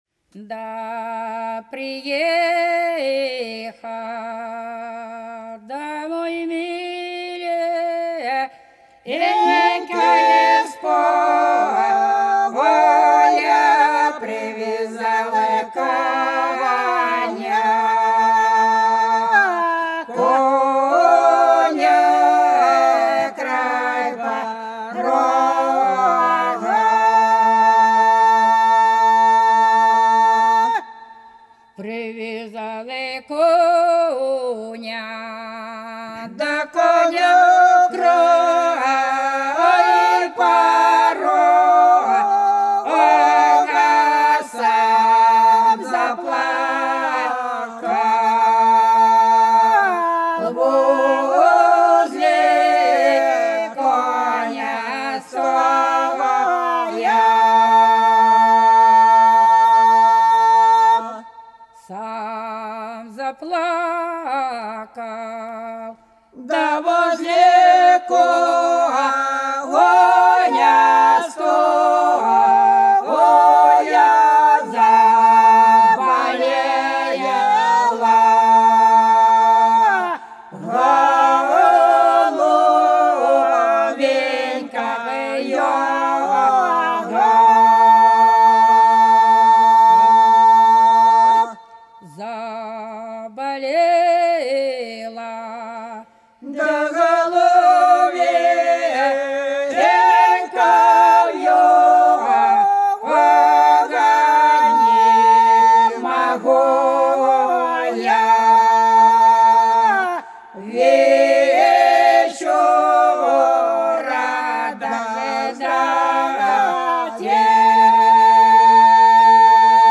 По-над садом, садом дорожка лежала Да приехал да миленький с поля - протяжная (с.Плёхово, Курская область)
10_Да_приехал_да_миленький_с_поля_(протяжная).mp3